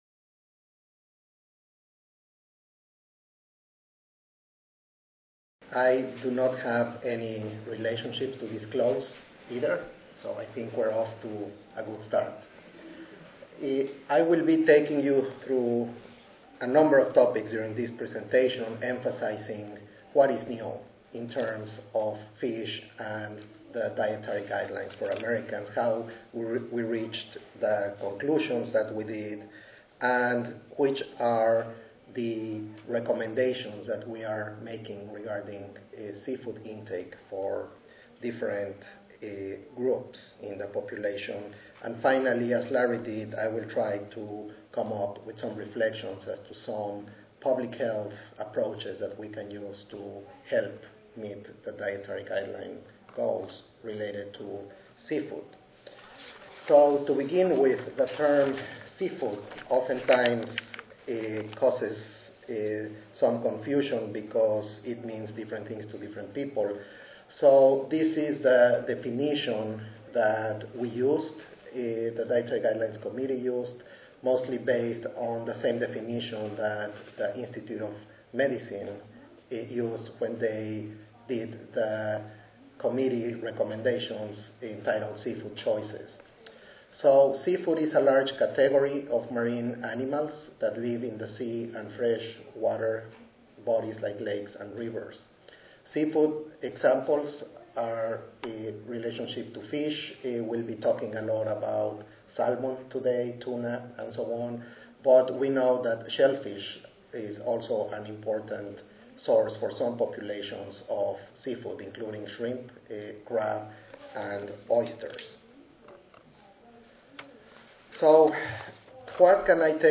The purpose of this session is to illustrate how the Dietary Guidelines for Americans, 2010 may be used as the basis for population-level chronic disease prevention strategies, using three examples of recommendations with potentially significant public health implications. Specifically, the panel members will discuss recommendations surrounding 1) sodium; 2) seafood; and 3) calorie balance/obesity prevention.